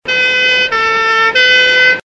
AMBULANCE SIREN.mp3
Ambulance siren blaring on the city streets, recorded in a cloudy night.
ambulance_siren_t1f.ogg